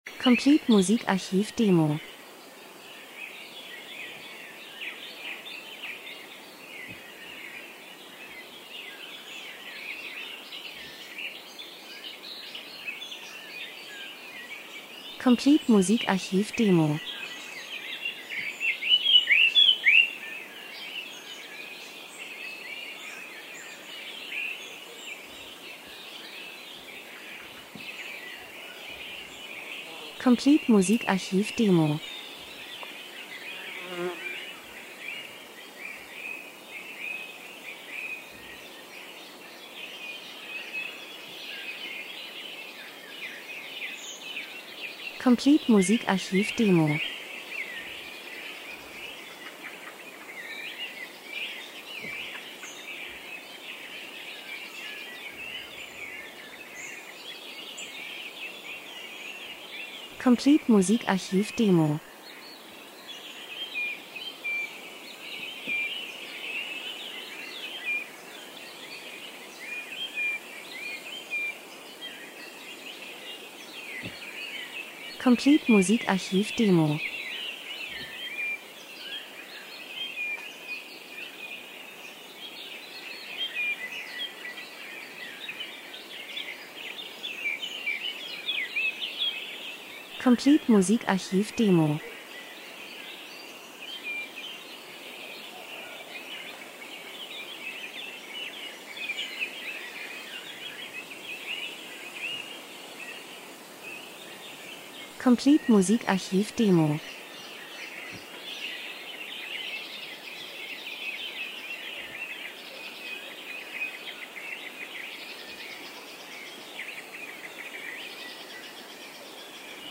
Sommer -Geräusche Soundeffekt Natur Wiese Vögel Insekten 02:52